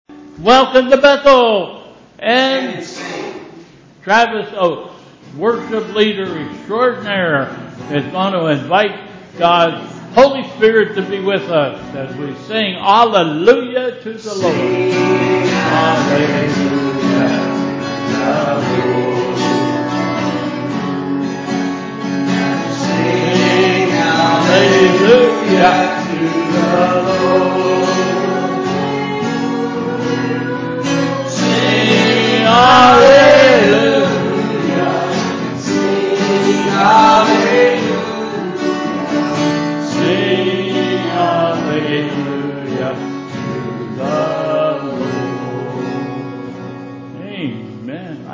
Bethel Church Service
Prelude: "Sing Alleluia to the Lord"